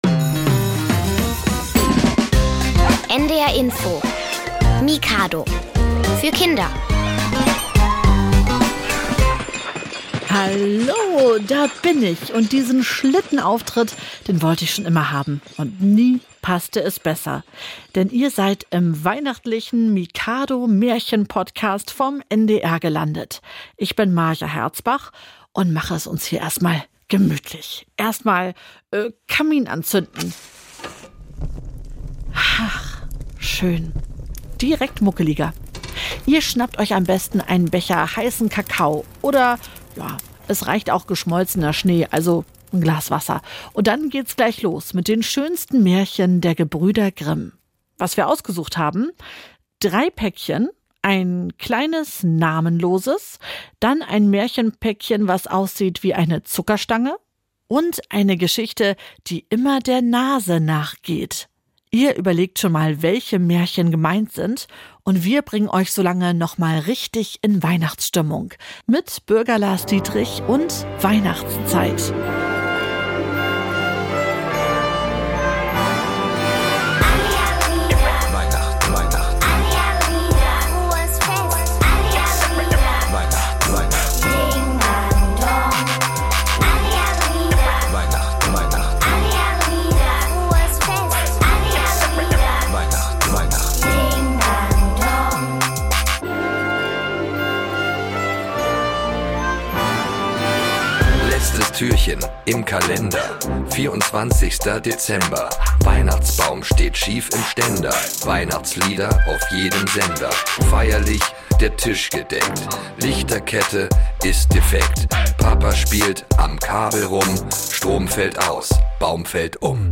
Märchen (3) Rumpelstilzchen/Schneeweisschen/Zwerg Nase RADIO (WE:53:46) ~ Hörspiele, Geschichten und Märchen für Kinder | Mikado Podcast